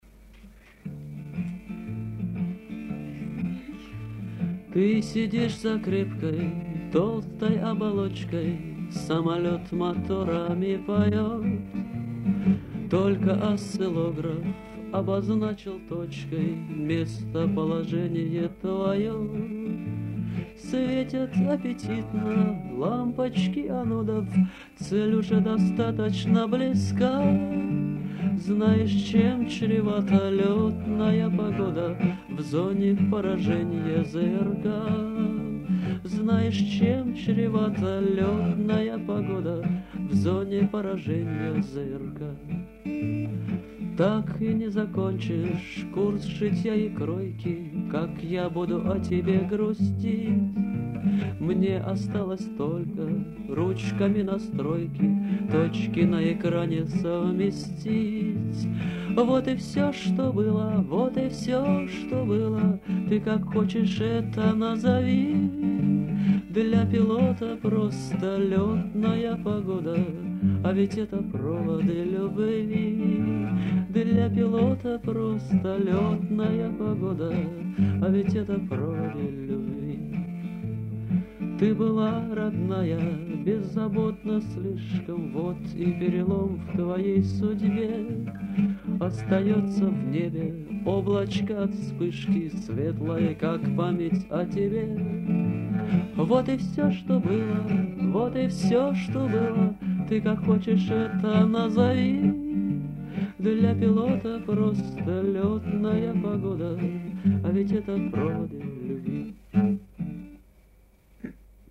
А он поет "....любовь, любовь...."
Прекрасный пейзаж, с удовольствием полюбовался им под душевную песню.